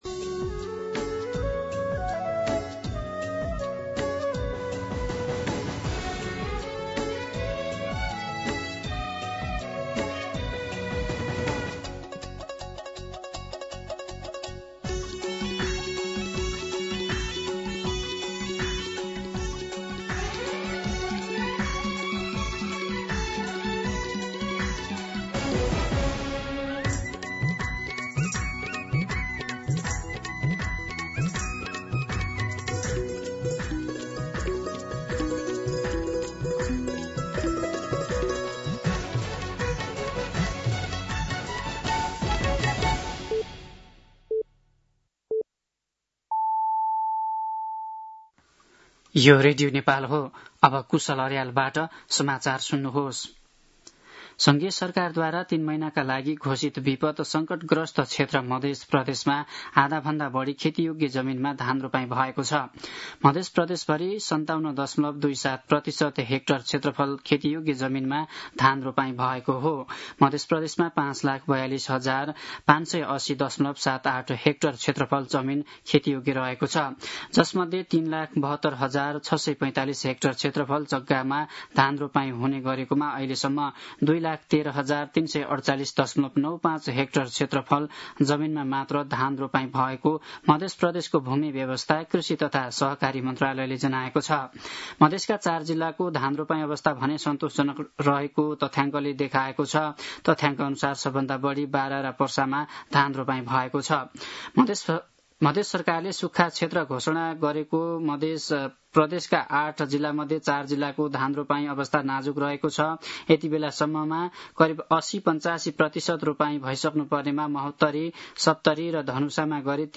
दिउँसो ४ बजेको नेपाली समाचार : १७ साउन , २०८२
4-pm-Nepali-News-1.mp3